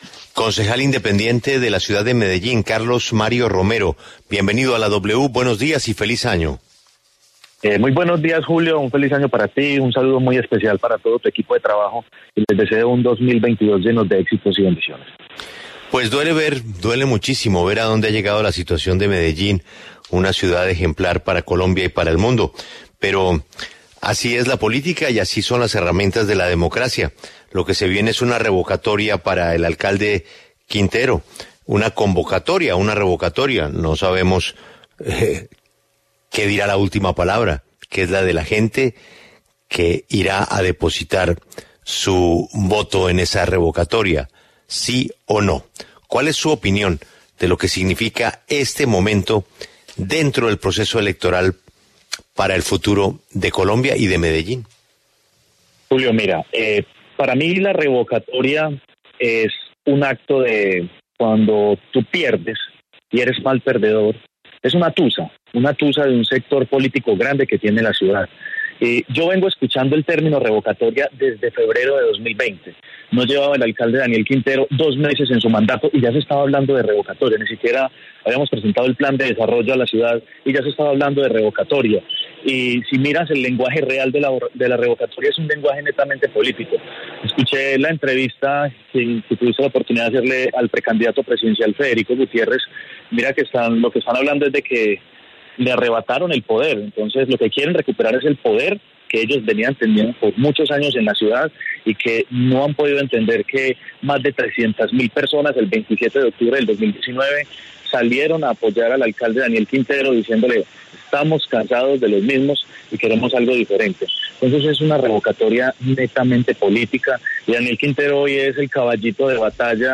Carlos Mario Romero, concejal de Medellín y uno de los más cercanos a Daniel Quintero, habló en La W sobre el aval de firmas para la revocatoria del alcalde Daniel Quintero.